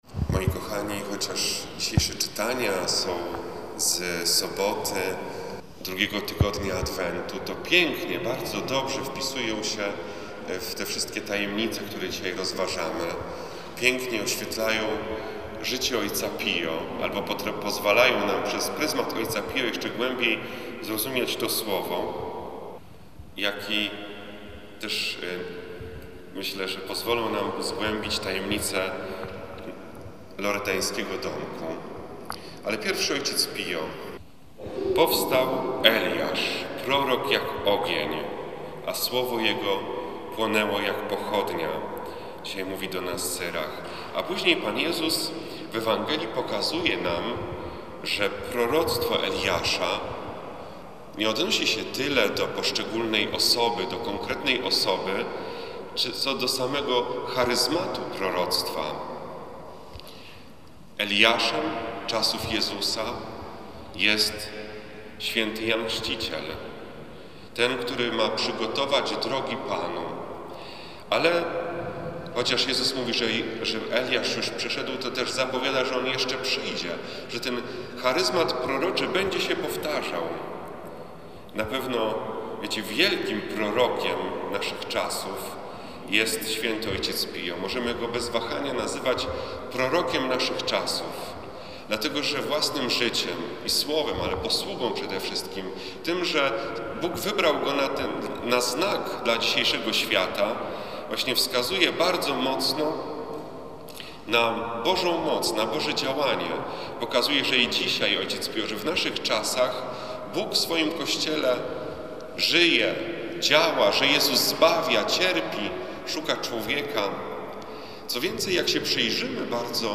W sobotę 10 grudnia, w Sanktuarium św. Ojca Pio na warszawskim Gocławiu, odbył się XII Adwentowy Dzień Skupienia.
homilia-na-str.mp3